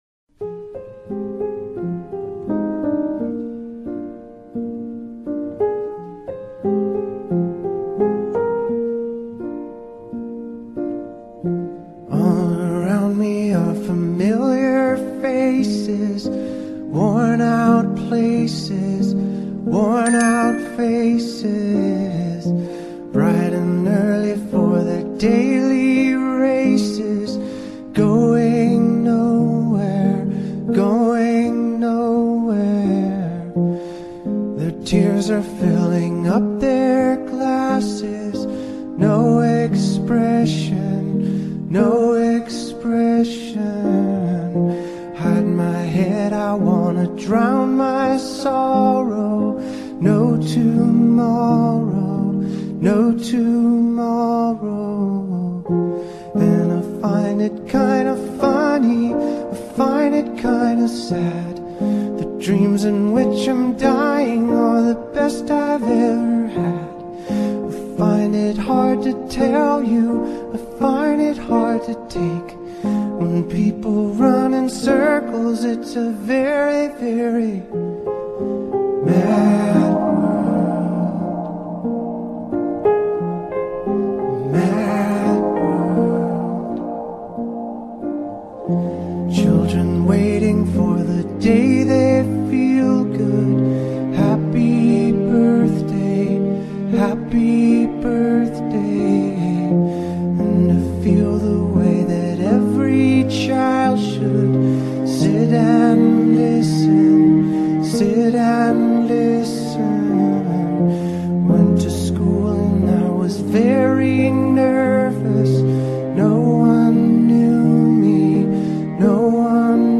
DarkPop